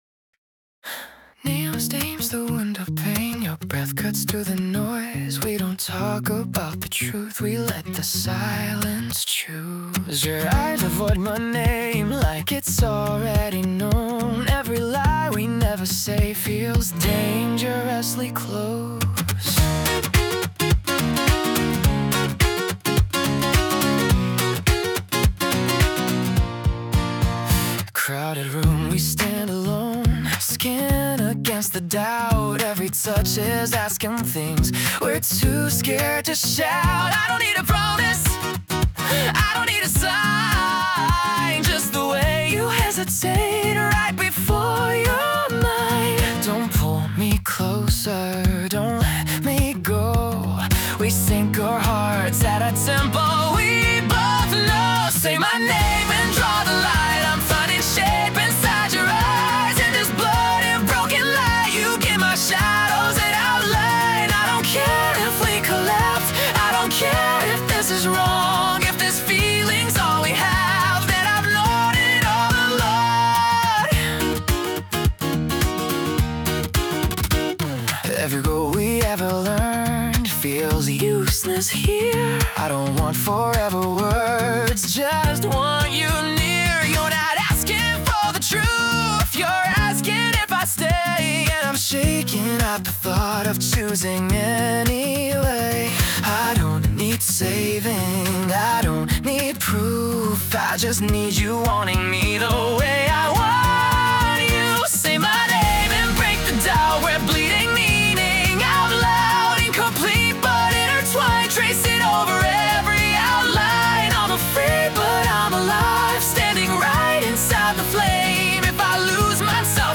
男性ボーカル洋楽洋楽 男性ボーカル作業BGMポップス切ない
著作権フリーオリジナルBGMです。
男性ボーカル（洋楽・英語）曲です。